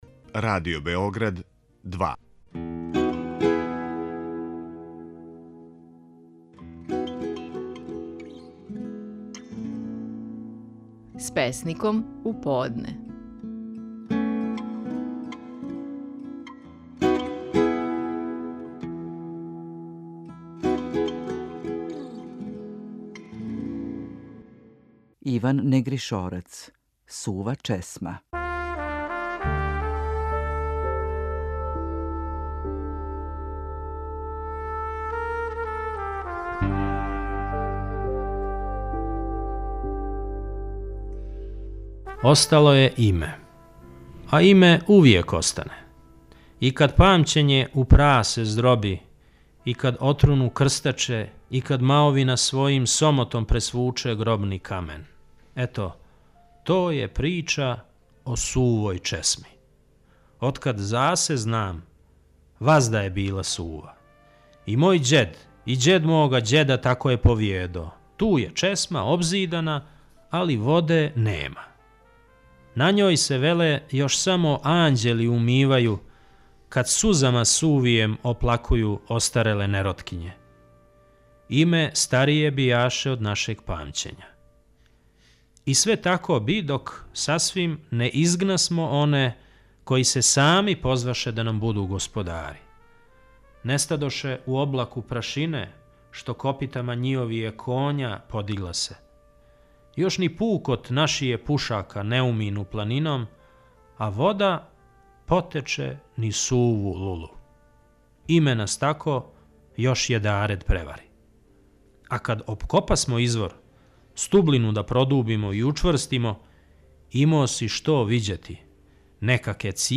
Стихови наших најпознатијих песника, у интерпретацији аутора.
У данашњој емисији слушамо како је стихове своје песме „Сува чесма" говорио Иван Негришорац.